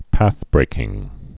(păthbrākĭng, päth-)